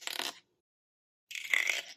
8. Краб скрипит клешнёй